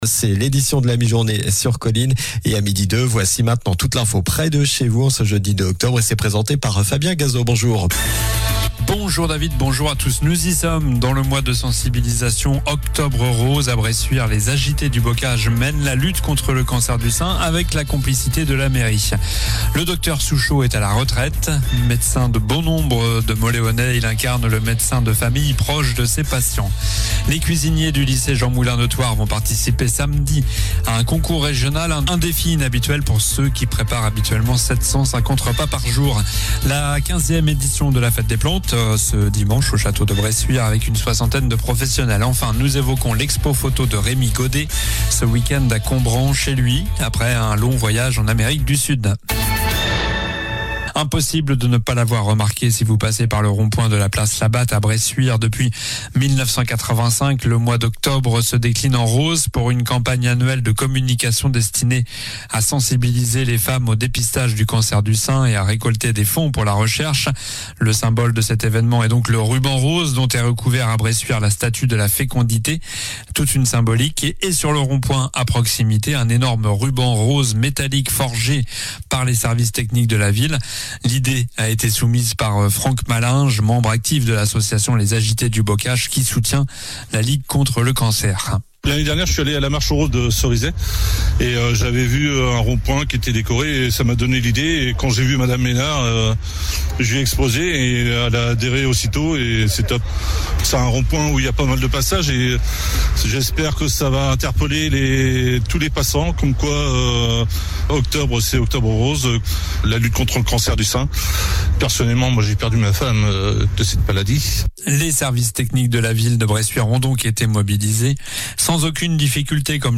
Journal du jeudi 02 octobre (midi)